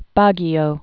(bägē-ō)